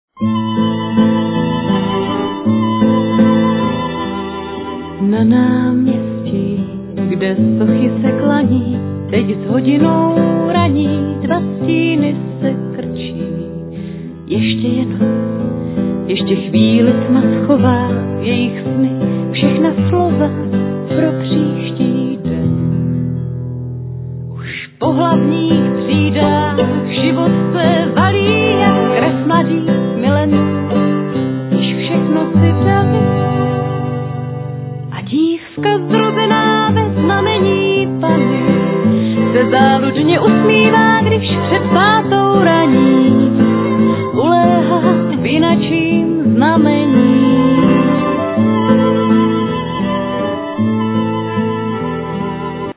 Vocals
Double bass
El.guitar
Drums
Ac.guitar
Accordion
Vocals, Flute
Violin, Viola
Saxophone